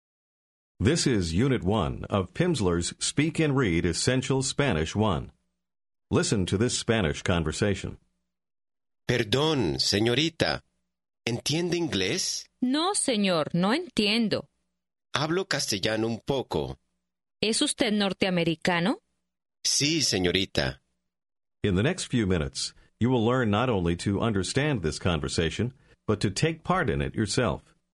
Аудио курс для самостоятельного изучения испанского языка.